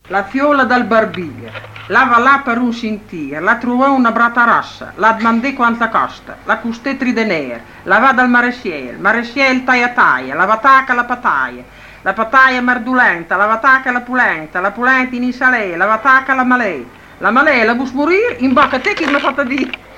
filastrocca - la fiola dal barbir.mp3